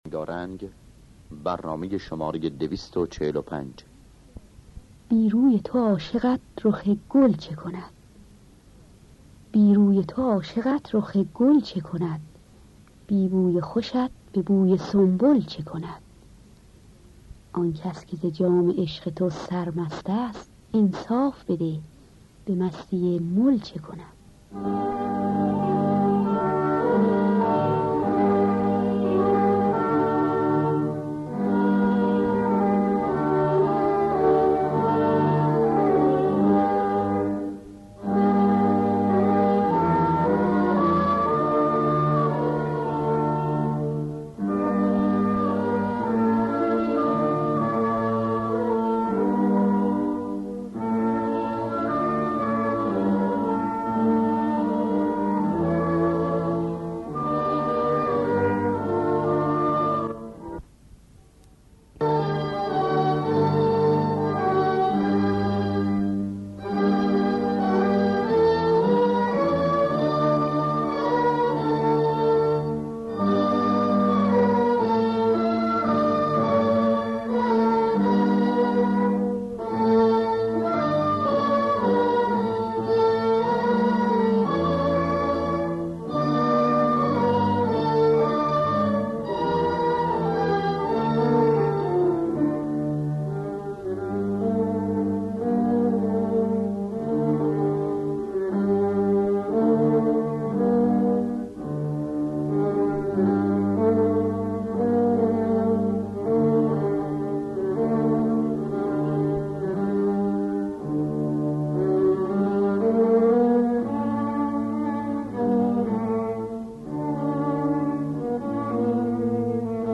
دانلود گلهای رنگارنگ ۲۴۵ با صدای بنان در دستگاه همایون.